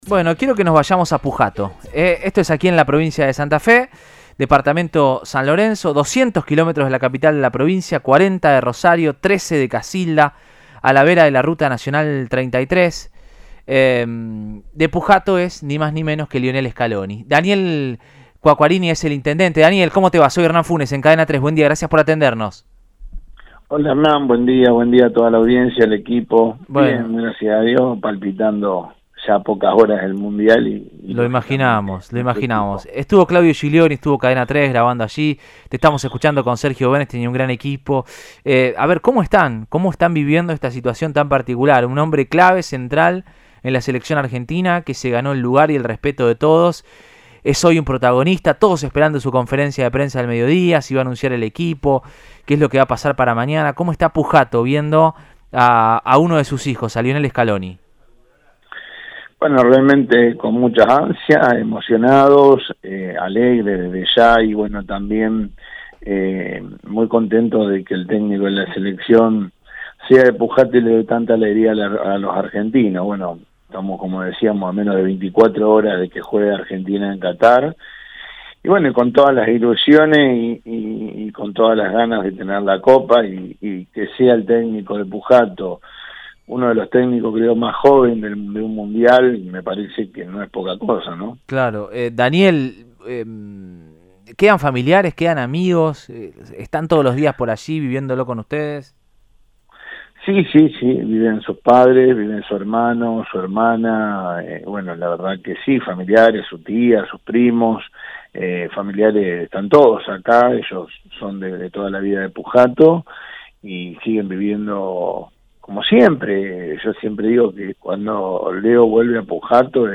Así lo dijo el intendente Daniel Quacquarini.